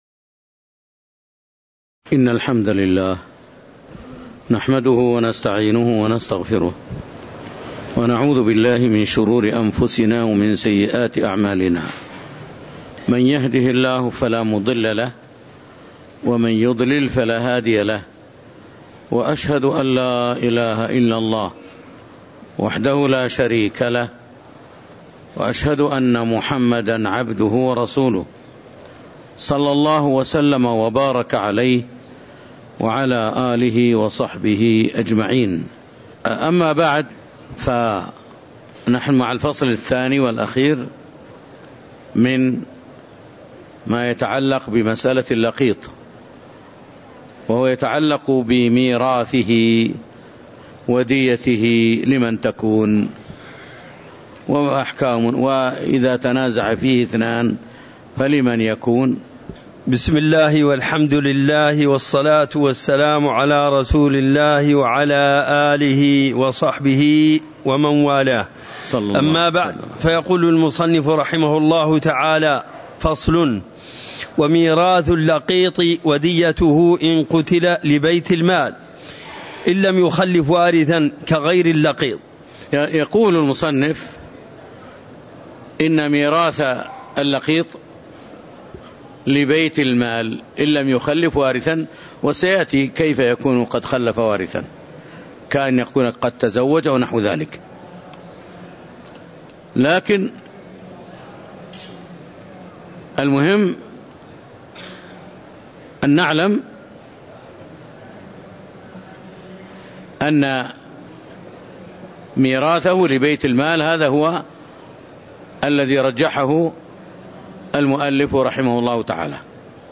تاريخ النشر ١٠ صفر ١٤٤٤ هـ المكان: المسجد النبوي الشيخ